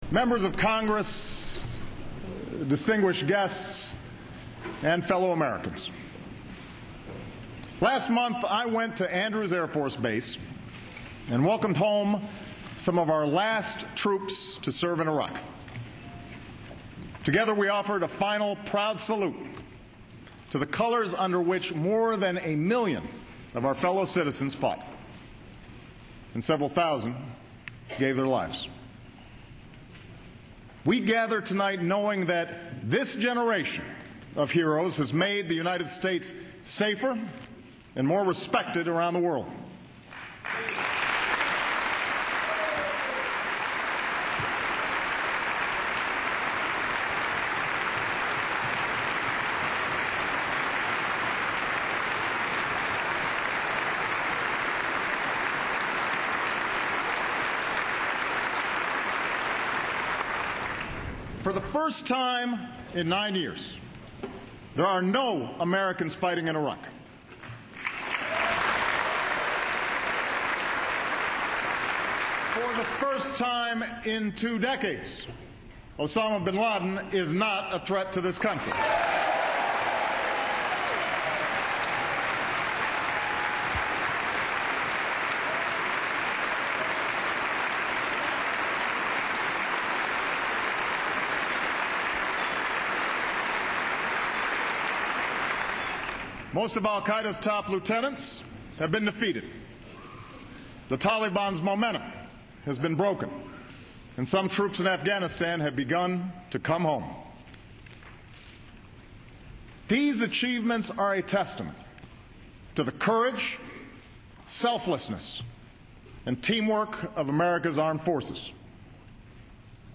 1月24日星期二晚上9点钟，美国总统奥巴马前往国会，向参众两院和全体美国人民，发表国情咨文讲话。